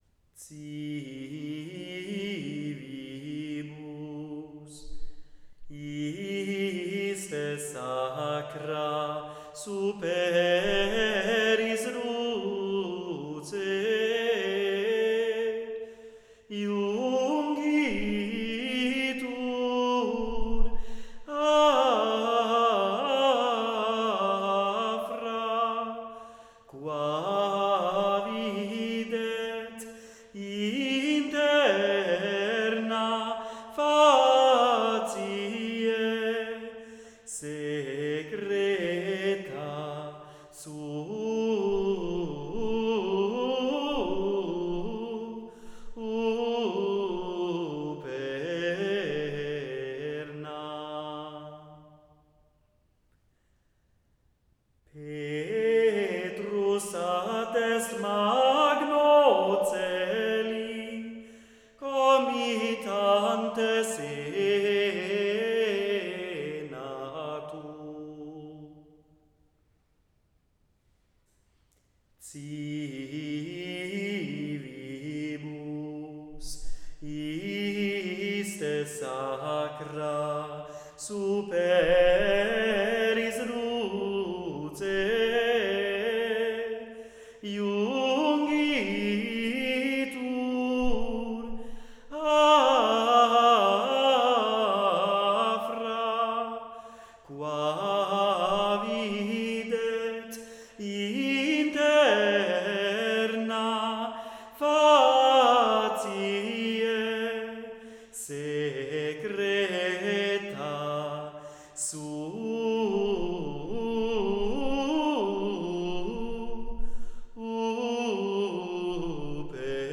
Responsorium_Civibus_iste.wav